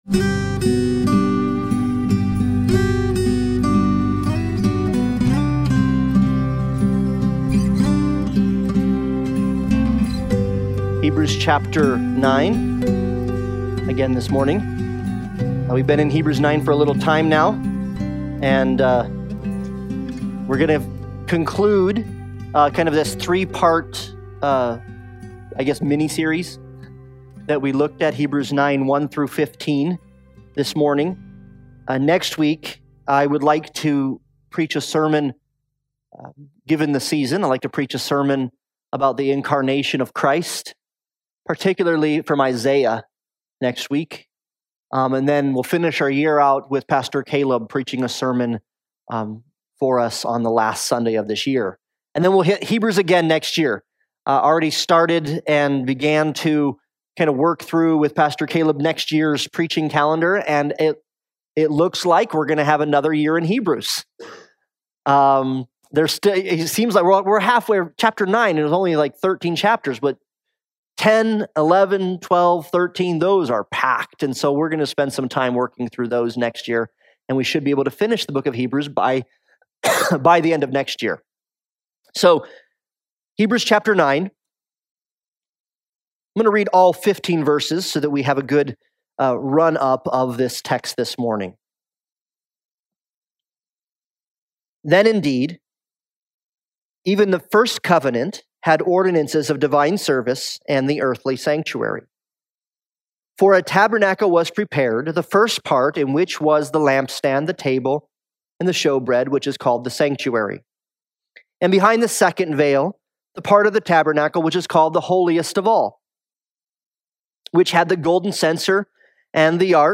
Hebrews 9:15 Service Type: Sunday Morning Worship Topics